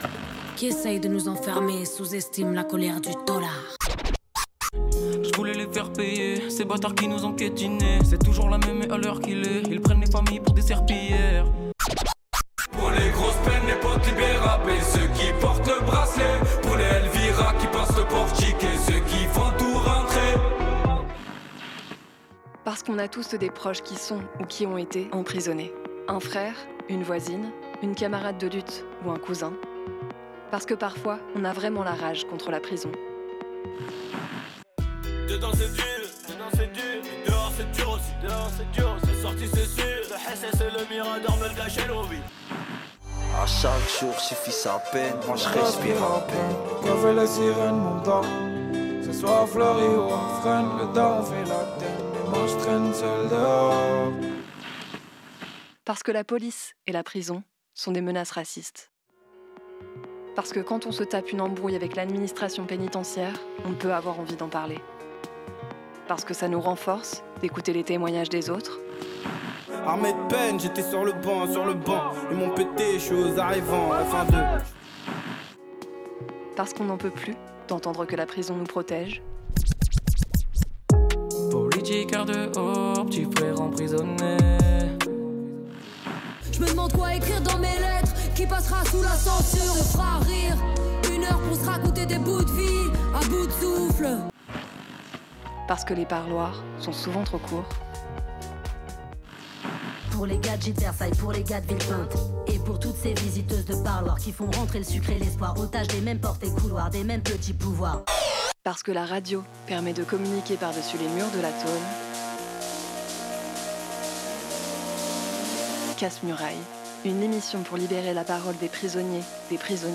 Aujourd'hui dans l'émission, on a parlé des régimes d'isolement en prison, et des stratégies du pouvoir pour diviser les prisonnier·es. Tout ça pour critiquer le projet du ministre des prisons et des tribunaux, visant à créér des quartiers spécifiques pour isoler des prisonnier·es étiqueté·es comme "narco". On a lu une lettre de Thierry Chatbi, publiée dans le livre A ceux qui se croient libres ; et aussi une lettre de Rédoine Faid publiée dans L'Envolée n°60